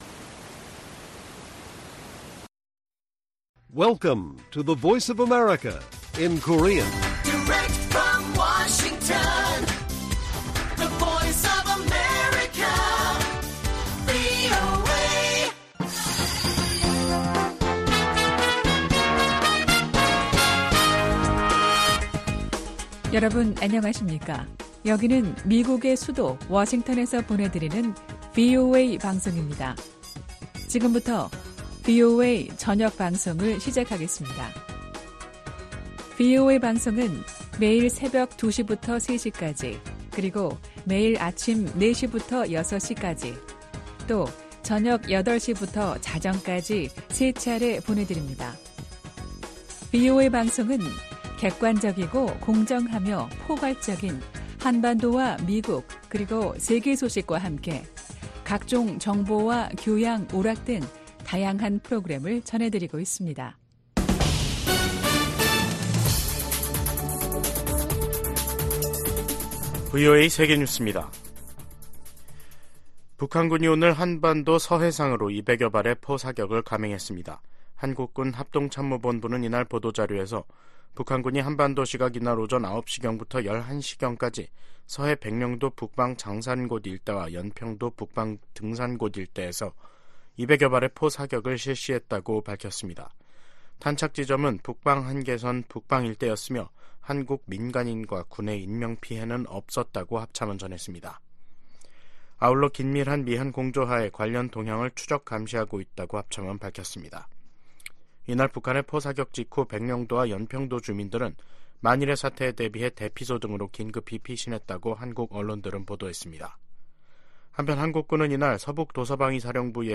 VOA 한국어 간판 뉴스 프로그램 '뉴스 투데이', 2024년 1월 5일 1부 방송입니다. 북한군이 5일 오전 9시부터 서북도서지역에서 해안포 200여발을 발사했습니다. 최근 예멘 후티 반군이 이스라엘을 향해 발사한 순항 미사일 파편에서 한글 표기가 발견됐습니다. 최근 러시아가 북한으로부터 탄도미사일을 제공받아 우크라이나 공격에 사용했다고 백악관이 밝혔습니다.